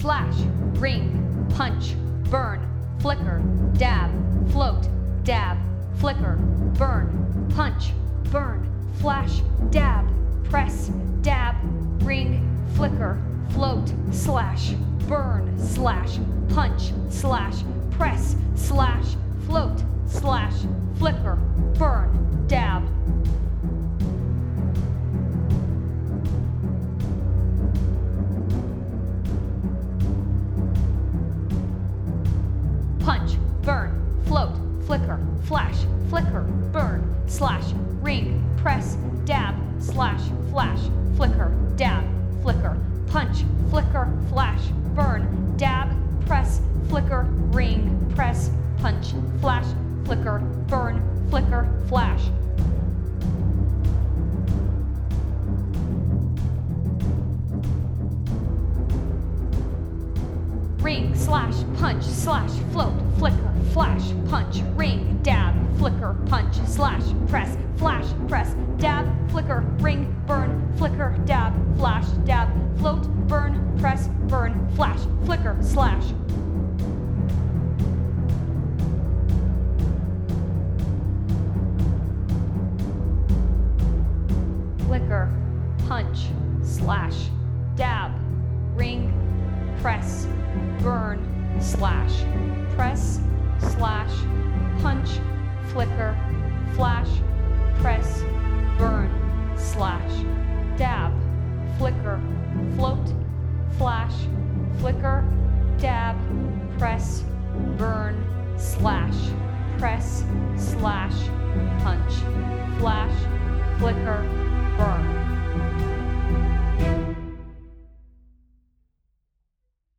As “I” has their biggest break down this music accompanies the movement based motifs.